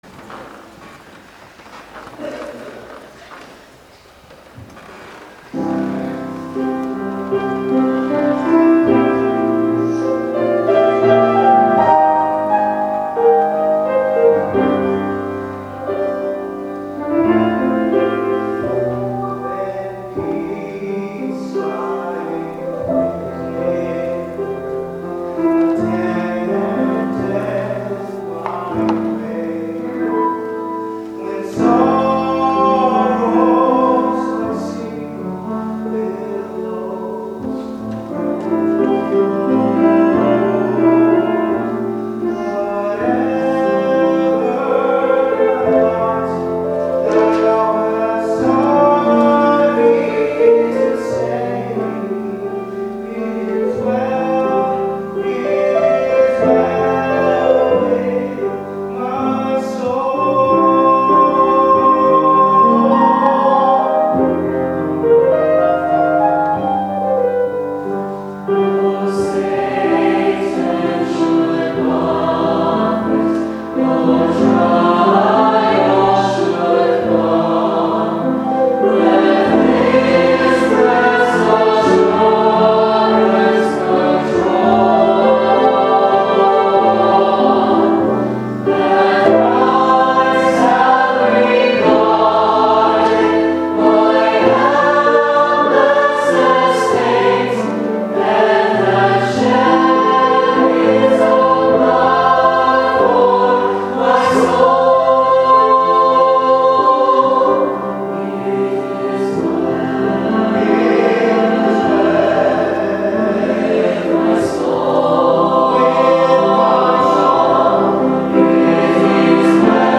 Listen to It Is Well, one of the songs the Sterling College Choir sang for us that day: